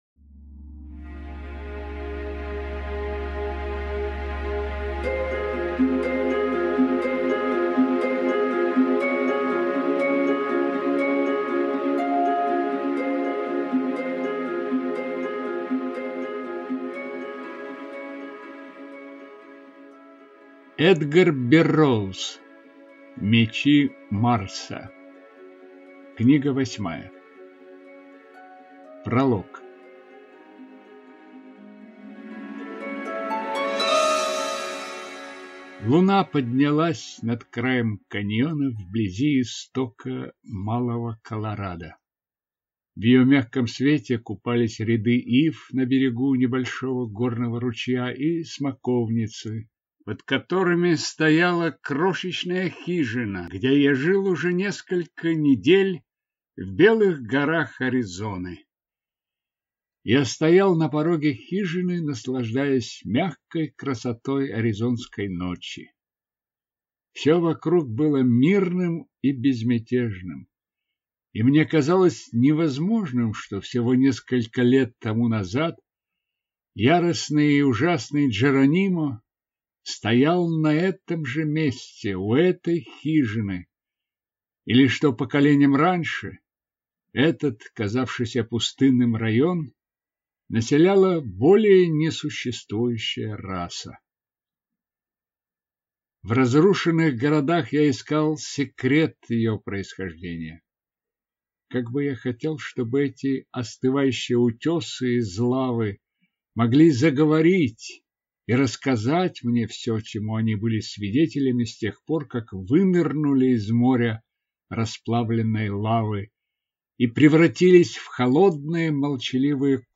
Аудиокнига Мечи Марса | Библиотека аудиокниг